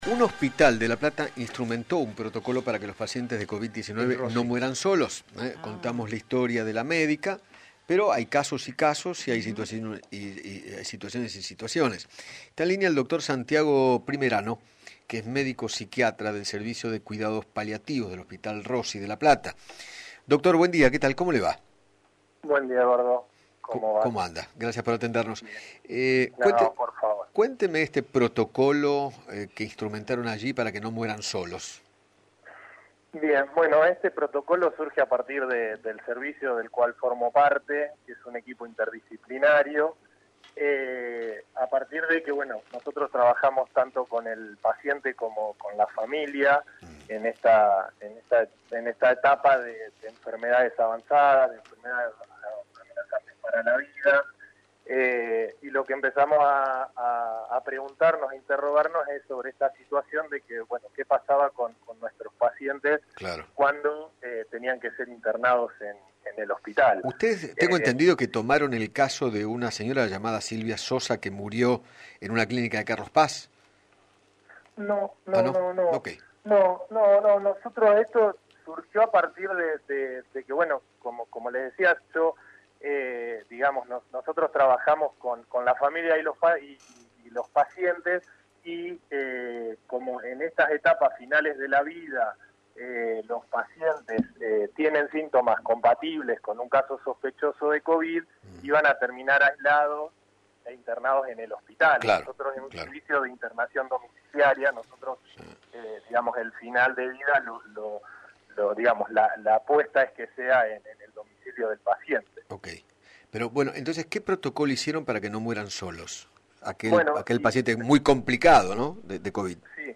dialogó con Eduardo Feinmann sobre el programa que elaboró la institución para que los pacientes más graves de coronavirus no pasen sus últimos días en soledad y los pueda acompañar un familiar.